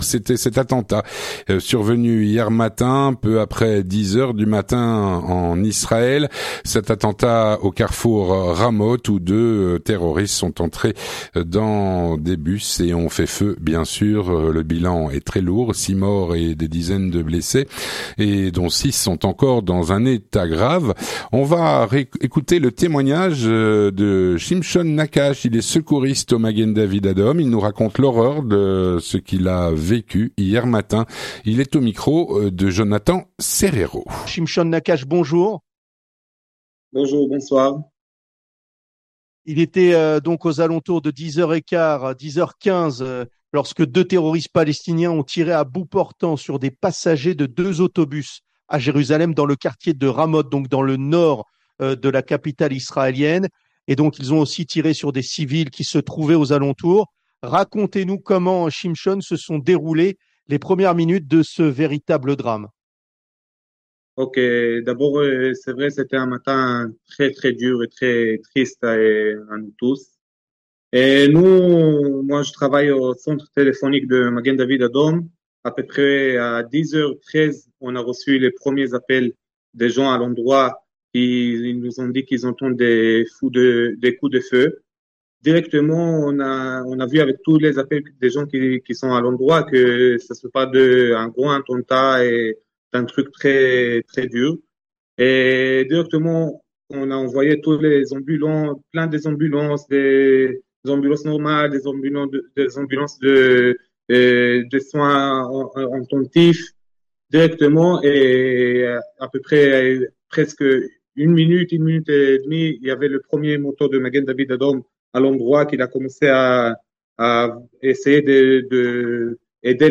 L'entretien du 18H - témoignage sur l’attentat perpétré au carrefour de Ramot, au nord de Jérusalem, hier matin.